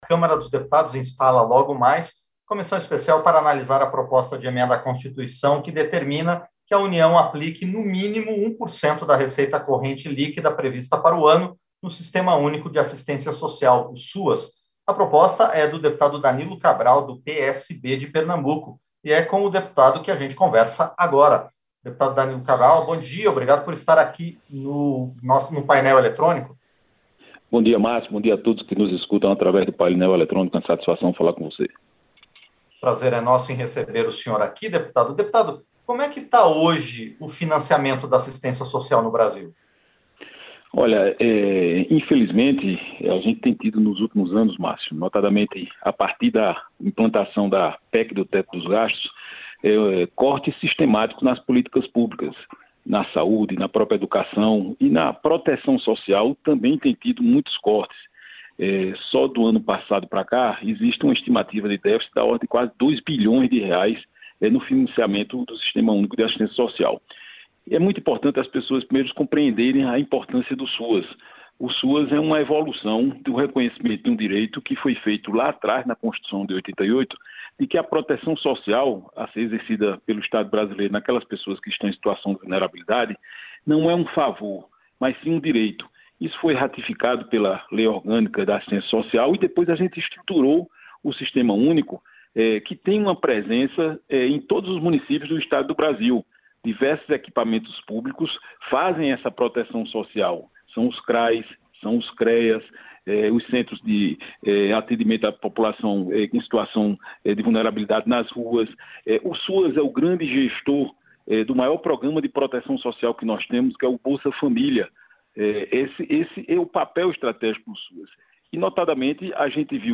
Entrevista - Dep. Danilo Cabral (PSB-PE)